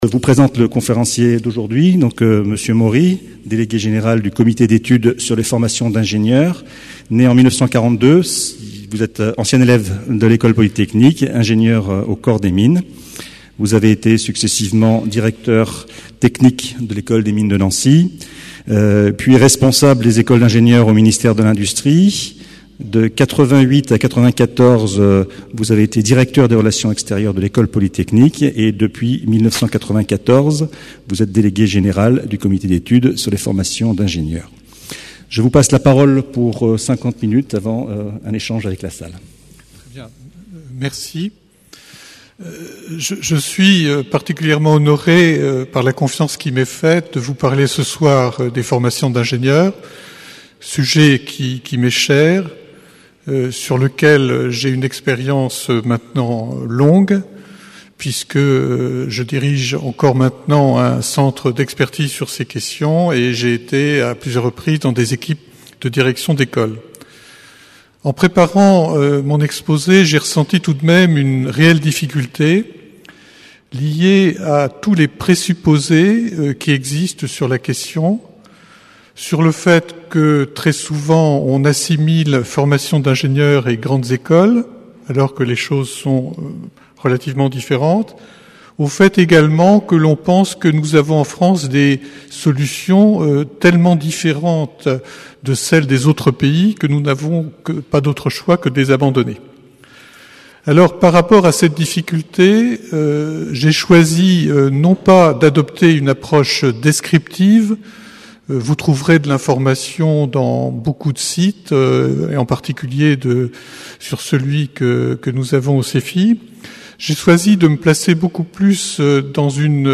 Une conférence du cycle : Qu'est-ce qu'un ingénieur aujourd'hui ?